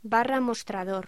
Locución: Barra mostrador